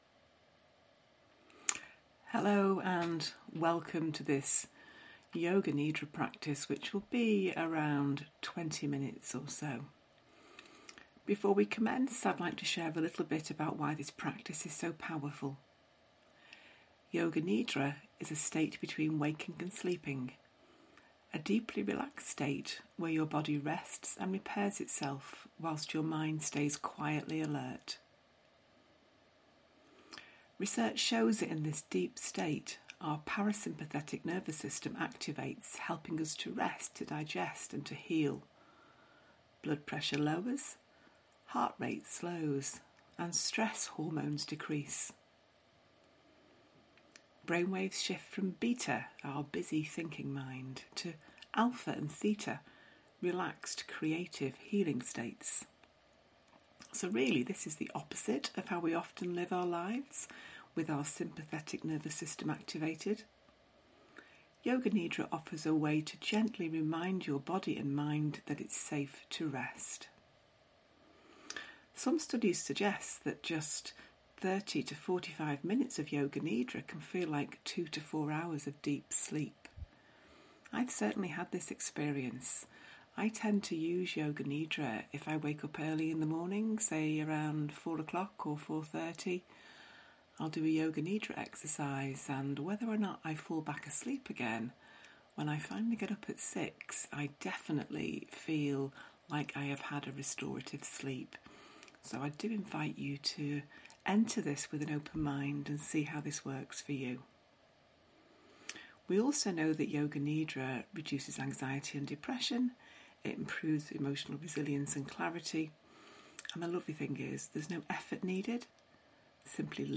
This 20-minute guided practice helps you reset your body and mind. Perfect if you’re short on time but need deep rest.